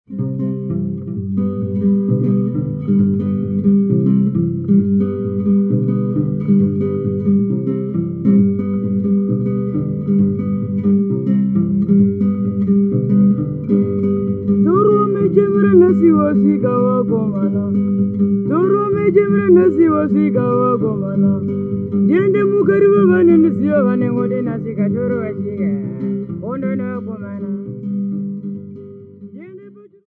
Folk music--Africa
Field recordings
Africa Zimbabwe Kariba f-rh
A topical song accompanied by guitar.